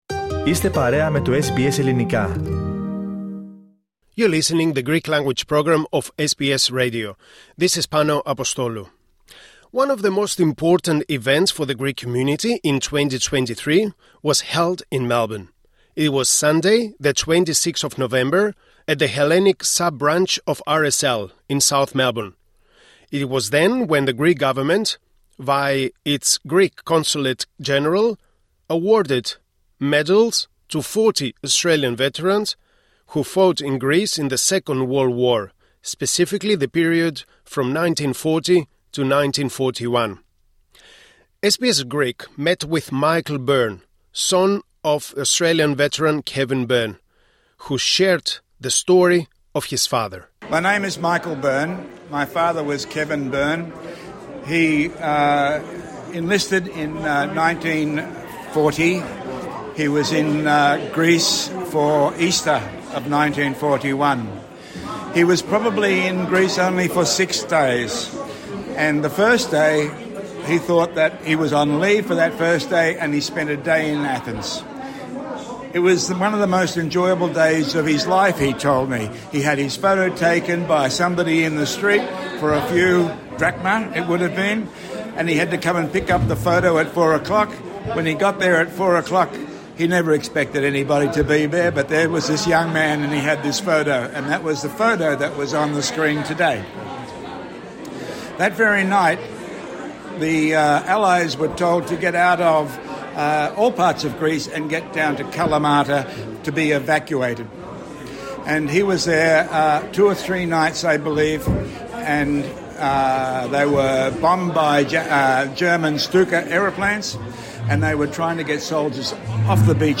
In 2023, one of the most important events for the Greek community of Melbourne was held in November.